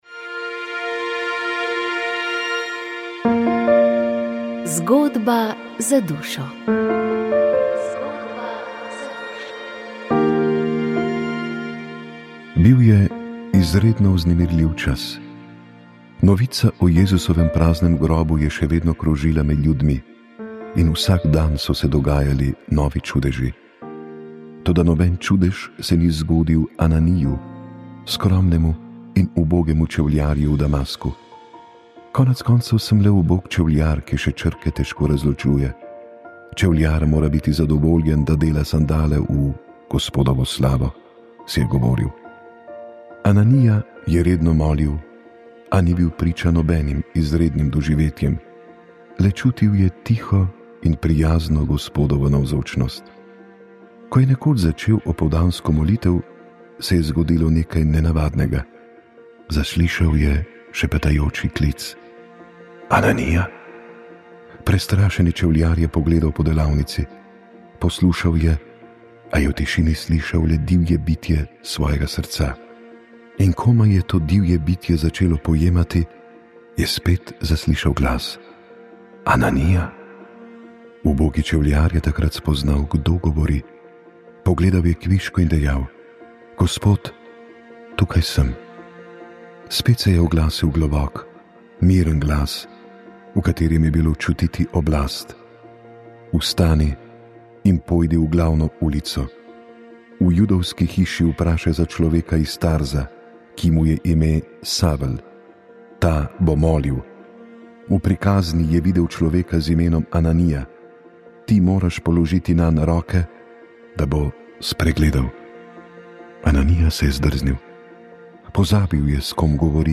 Gostili smo predsednike treh strank takoimenovane razvojne koalicije, in sicer predsednika Nove Slovenije Jerneja Vrtovca, predsednico SLS Tino Bregant in predsednika Fokusa Marka Lotriča. Za prihodnje volitve so stopili skupaj, mi pa bomo slišali nekaj njihovih stališč do aktualnih težav, v katerih se je znašla Slovenija.